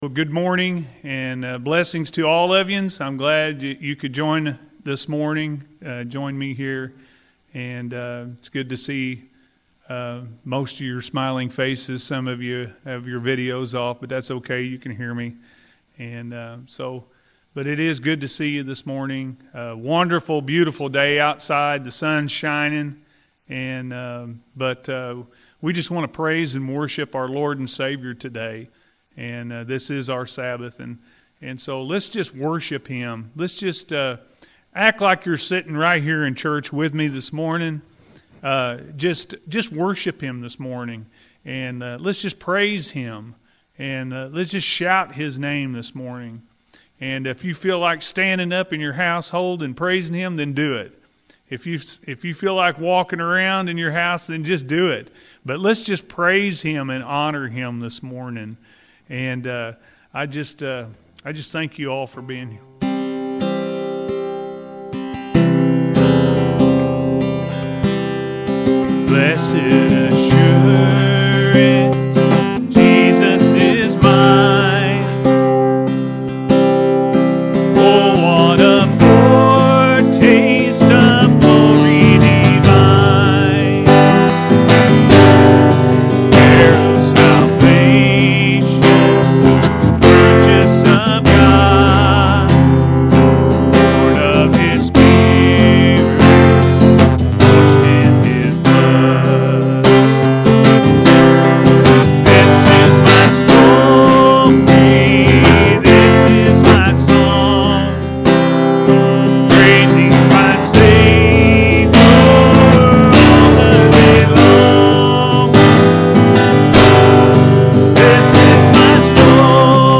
Do You Love Me?-A.M. Service – Anna First Church of the Nazarene